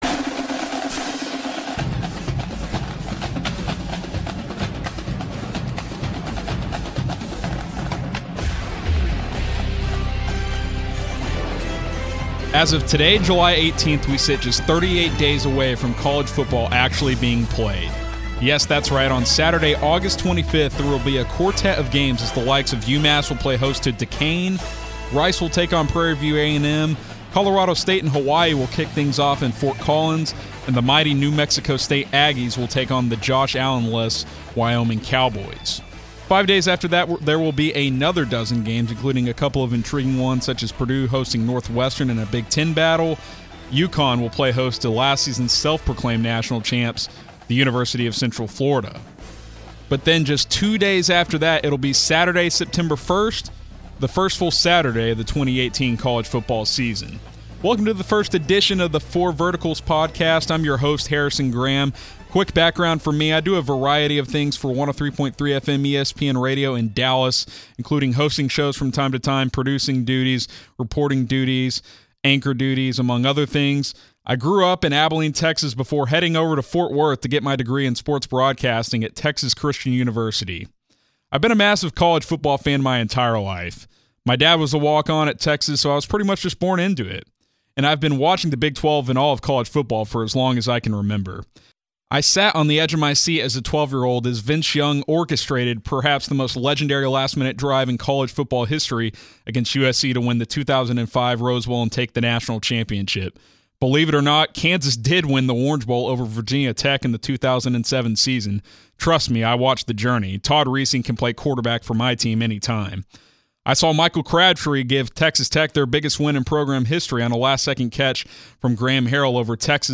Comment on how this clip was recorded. He ends episode 1 with some conversations he had with top Big 12 players at Big 12 Media Days about the popular game Fortnite. He also gave his All-Big 12 Fortnite squad.